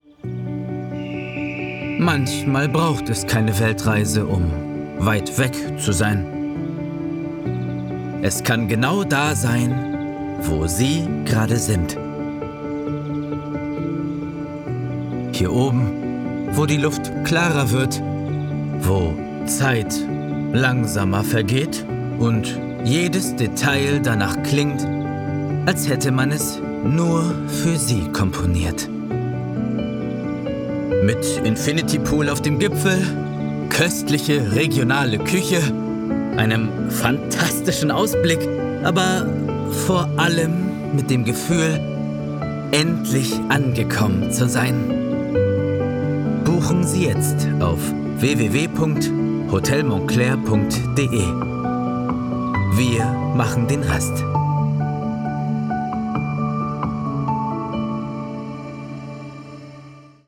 Ruhige, entspannte Werbestimme mit sanften Betonungen und fließender Intonation.